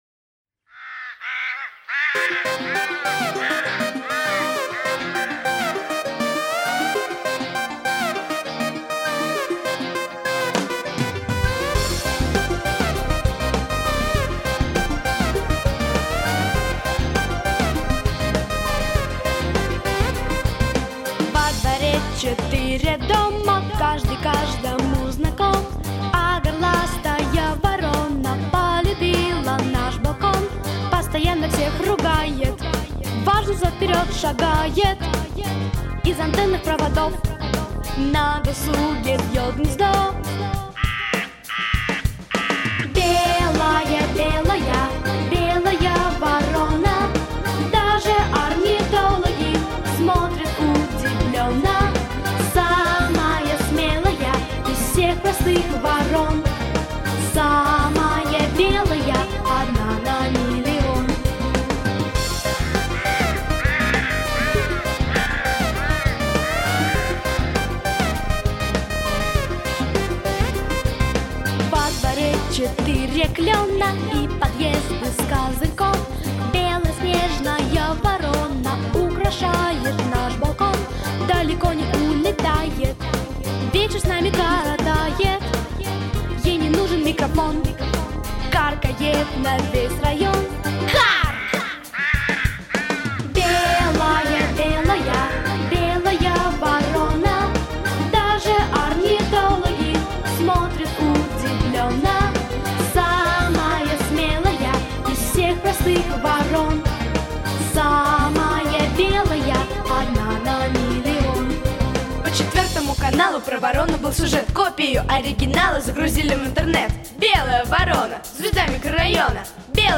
Детские песни